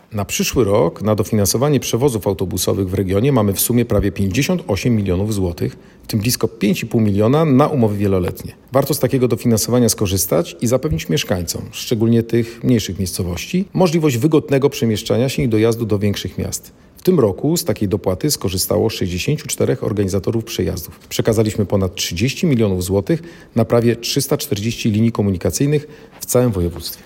Mówi Adam Rudawski, wojewoda zachodniopomorski.